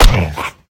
Minecraft Version Minecraft Version latest Latest Release | Latest Snapshot latest / assets / minecraft / sounds / mob / wolf / big / hurt2.ogg Compare With Compare With Latest Release | Latest Snapshot
hurt2.ogg